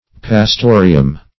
Search Result for " pastorium" : The Collaborative International Dictionary of English v.0.48: Pastorium \Pas*to"ri*um\, n. [See Pastor ; cf. Auditorium .]